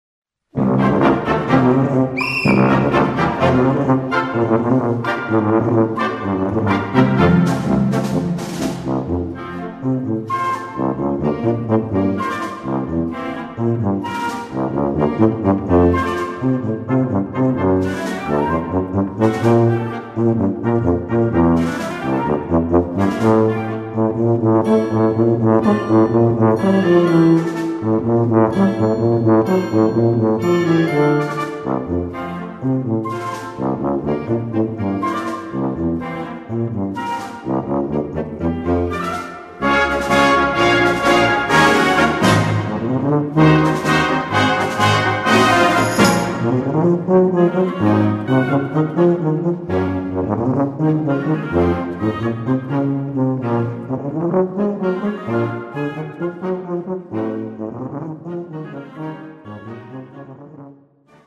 Gattung: Tubasolo
Besetzung: Blasorchester